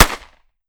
9mm Micro Pistol - Gunshot B 002.wav